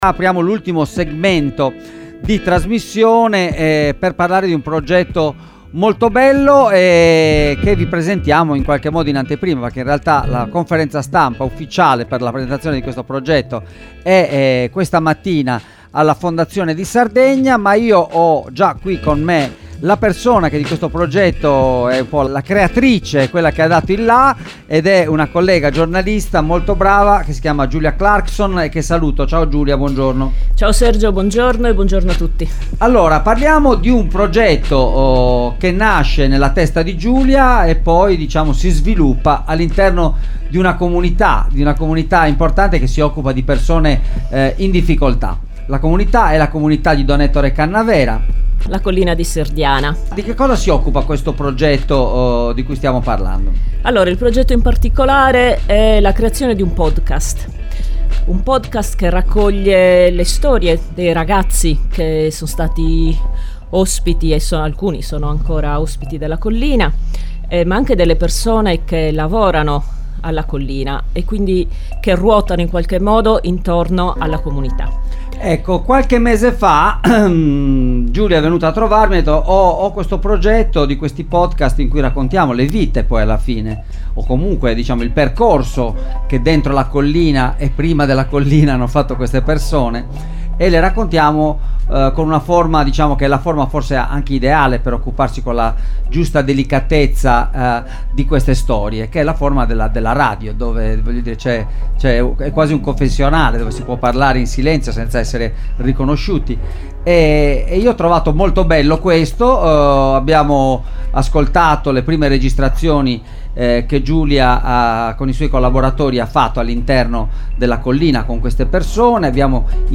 sono intervenuti questa mattina ai nostri microfoni per raccontarci la nascita di questo progetto e ripercorrere la storia della comunità che da 26 anni accoglie minori che hanno commesso reati e offre loro un’alternativa al carcere